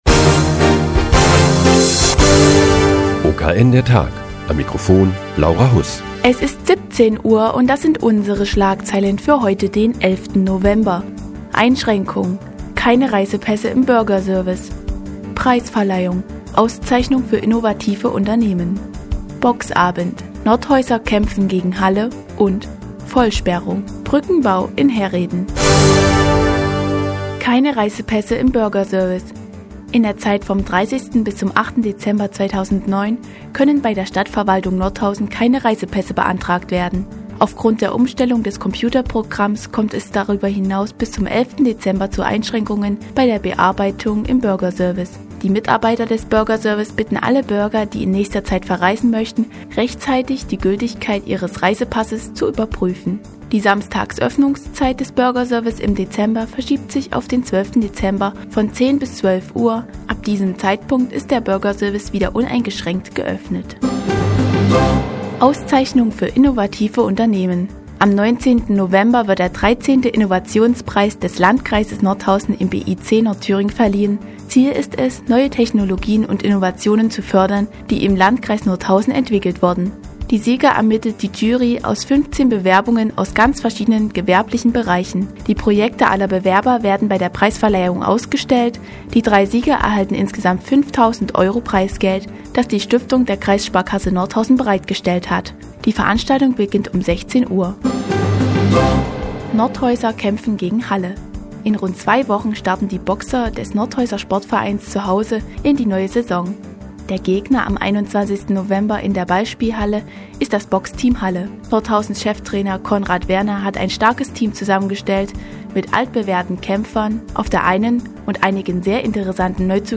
Die tägliche Nachrichtensendung des OKN ist nun auch in der nnz zu hören. Heute geht es um eine Auszeichnung für innovative Unternehmen und ein Boxturnier in Nordhausen.